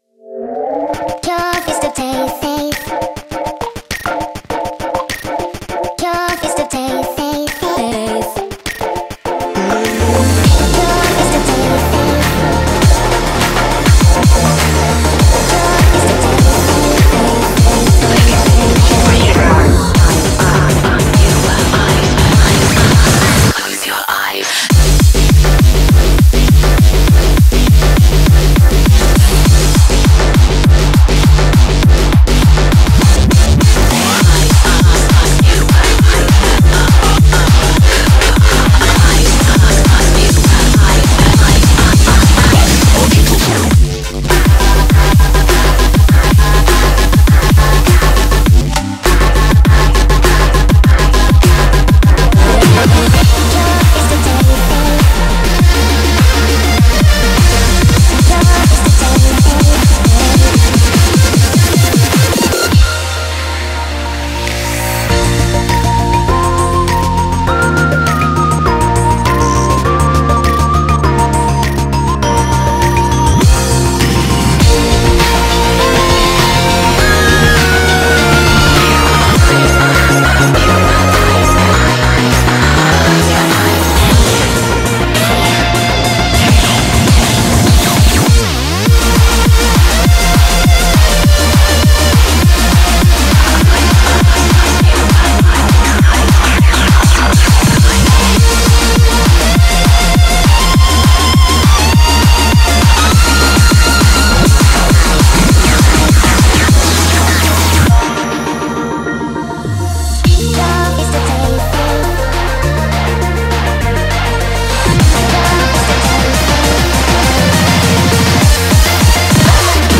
BPM101-202
Audio QualityPerfect (High Quality)